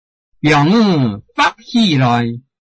臺灣客語拼音學習網-客語聽讀拼-饒平腔-入聲韻
拼音查詢：【饒平腔】fag ~請點選不同聲調拼音聽聽看!(例字漢字部分屬參考性質)